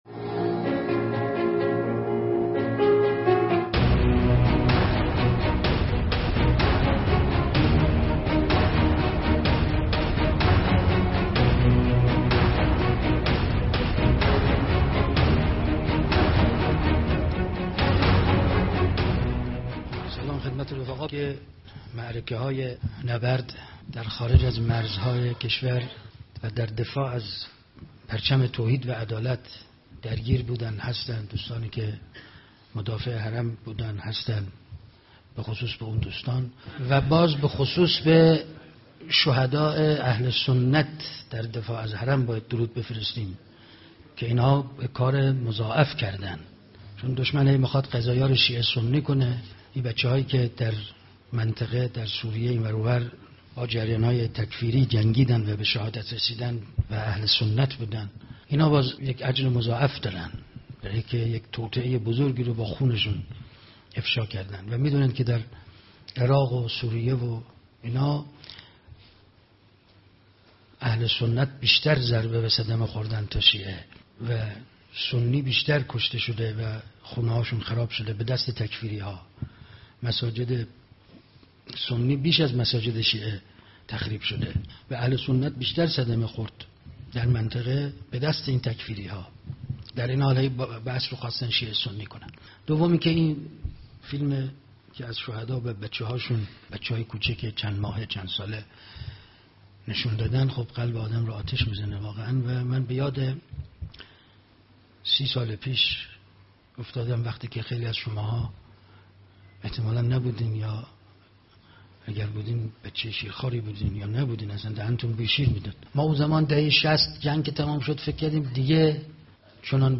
پرسش و پاسخ (20)